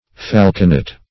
Falconet \Fal"co*net\, n. [Dim. of falcon: cf. F. fauconneau,